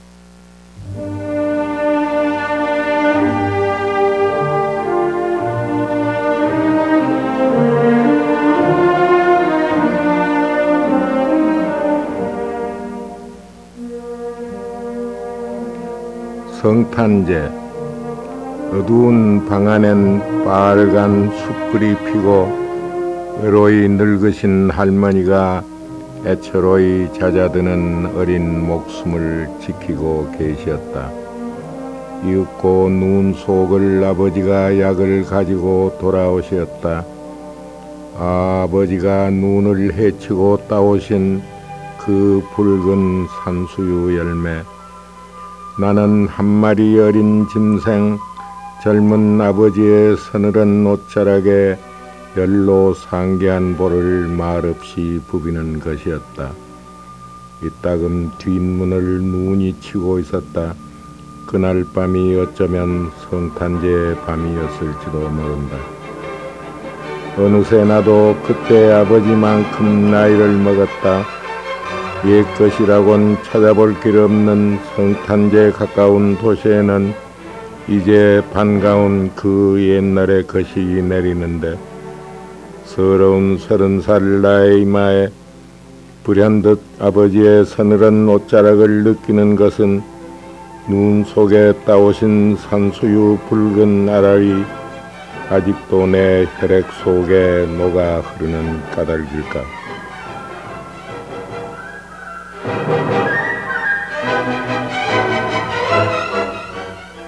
김종길 자작시 낭송 파일
<성탄제>를 시인이 직접 낭송하였습니다.